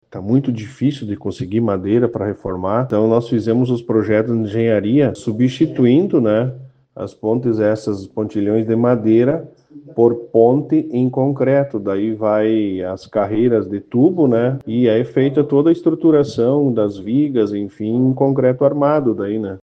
Geder Mori explica que são estruturas de madeira que passam a contar com tubos e parte de concreto. (Abaixo, sonora Geder).